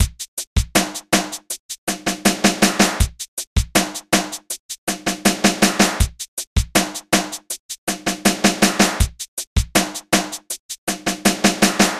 快拍
描述：用DSK mini drumz2制作的 "快速旋律 "的节拍。
Tag: 80 bpm Hip Hop Loops Drum Loops 2.20 MB wav Key : Unknown